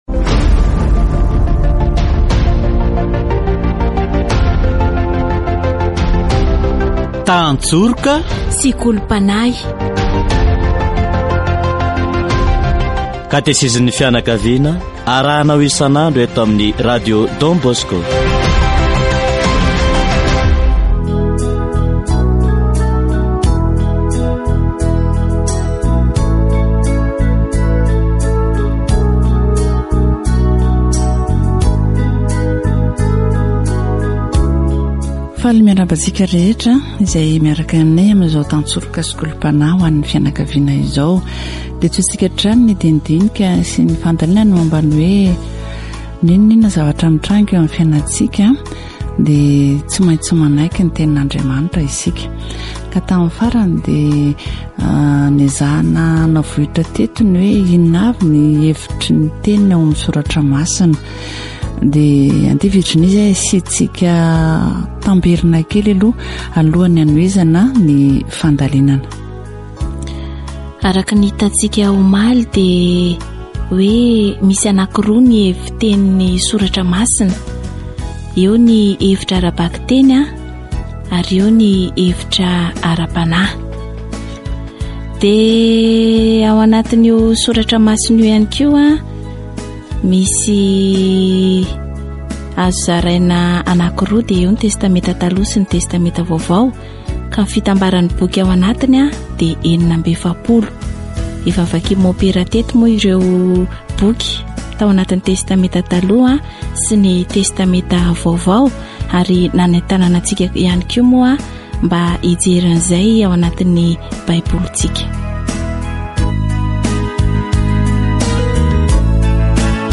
Catégorie : Approfondissement de la foi
La voie du salut, dans l'Ancien Testament, repose principalement sur la préparation à la venue du Christ Sauveur du monde. Catéchèse sur la parole de Dieu